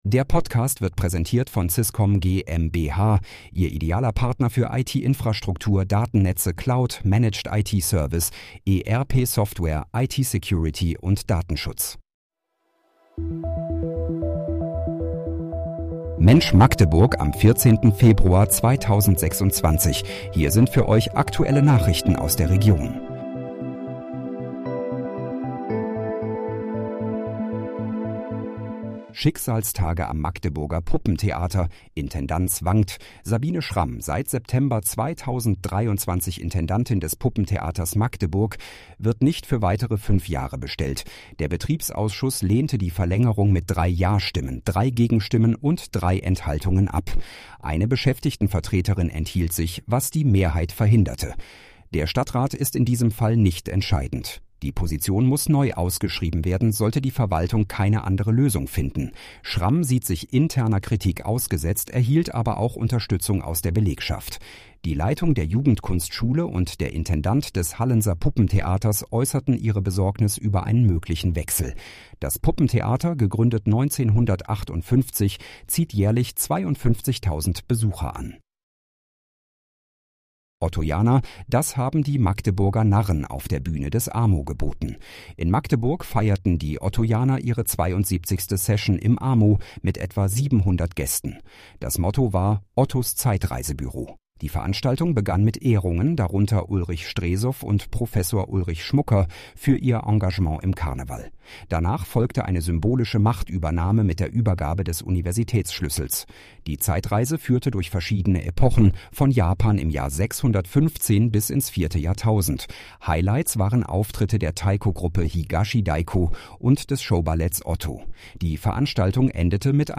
Mensch, Magdeburg: Aktuelle Nachrichten vom 14.02.2026, erstellt mit KI-Unterstützung